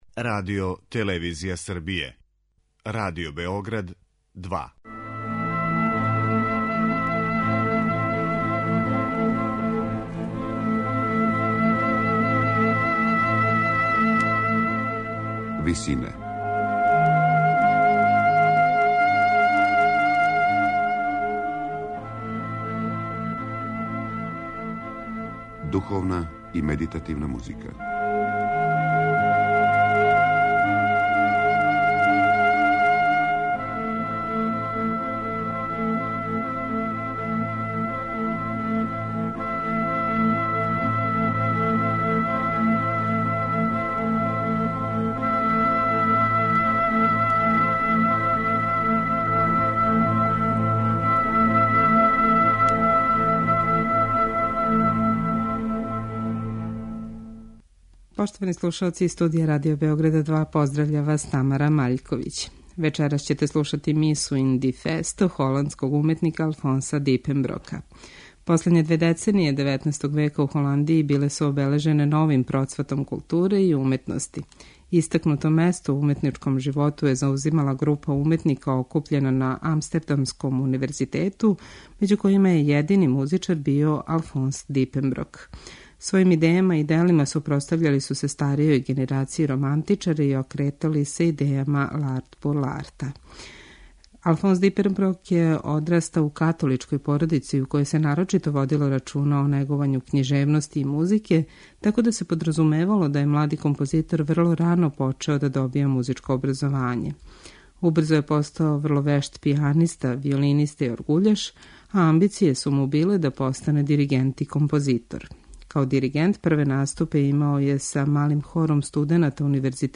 Миса ин ди Фесто за соло тенор, двоструки мушки хор и оргуље је монументална композиција, која је означавила својеврсну прекретницу у историји католичке црквене музике у Холандији.
медитативне и духовне композиције